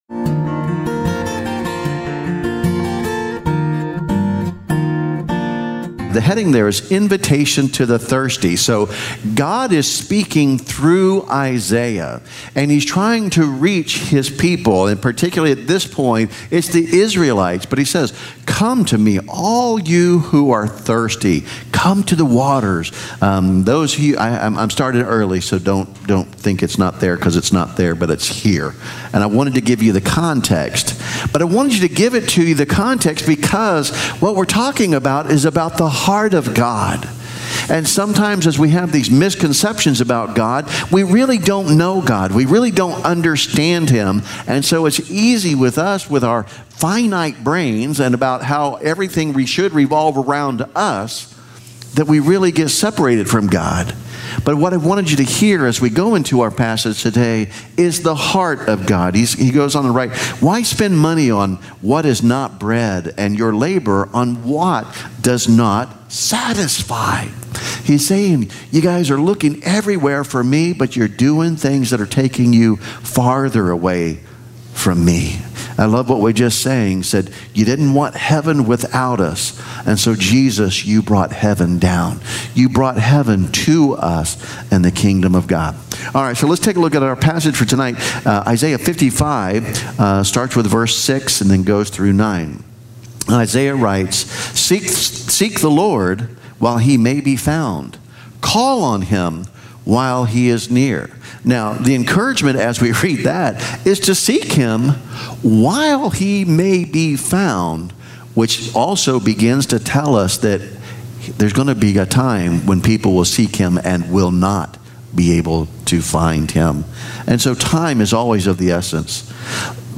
God On Demand - First United Methodist Church of Spring Hill